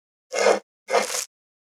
556魚切る,肉切りナイフ,
効果音